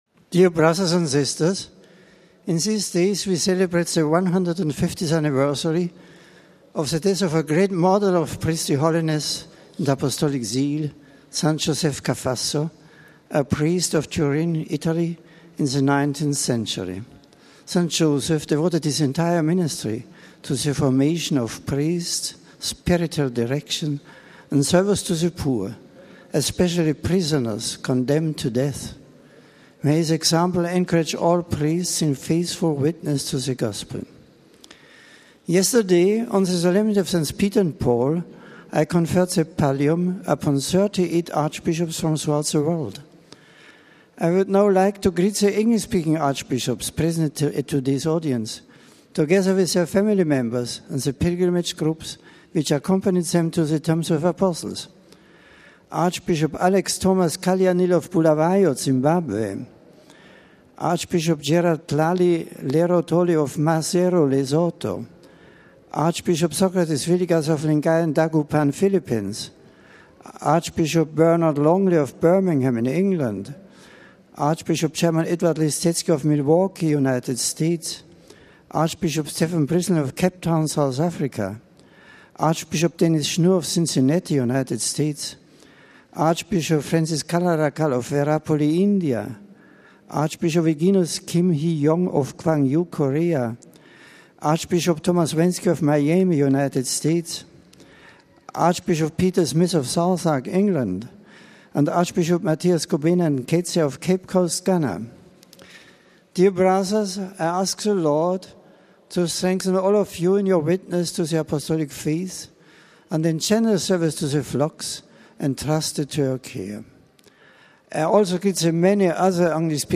The General Audience of the last day of June was held in the open in St. Peter’s Square in Rome. It began with aides taking turns reading a scripture passage in various languages. One of the aides greeted the Pope on behalf of the English-speaking pilgrims, and presented the various groups to him. Pope Benedict then delivered this discourse in English.